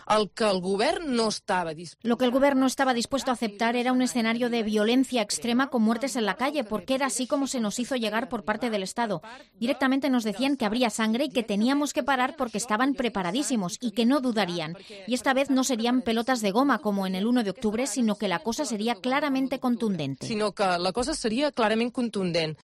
Rovira ha lanzado estas acusaciones en una entrevista en Rac1, en donde ha asegurado que el Ejecutivo hizo llegar al Gobierno de Carles Puigdemont estas amenazas por "múltiples vías", aunque no ha desvelado las fuentes pese a las preguntas de los periodistas: son "fuentes muy contrastadas y fiables", ha dicho, y ha dejado en el aire desvelar en el futuro estas fuentes.